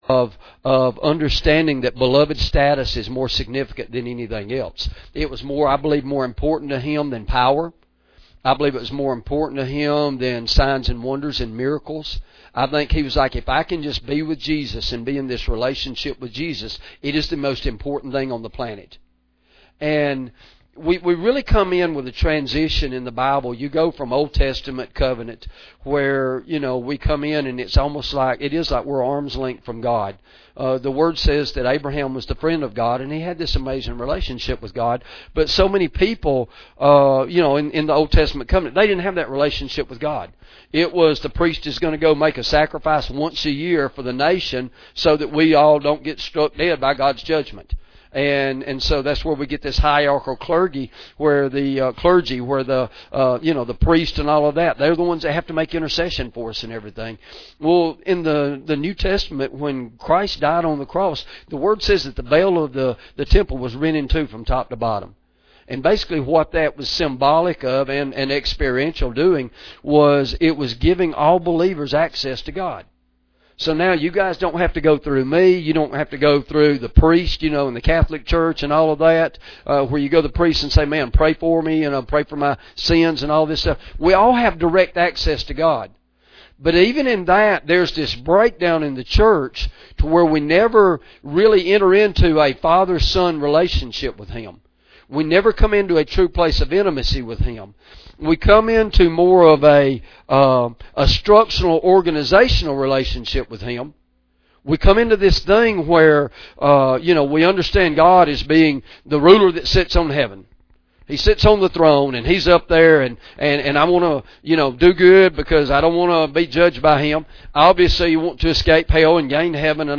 Sermon Audio Downloads | Victory Fellowship